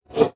Select Scifi Tab 16.wav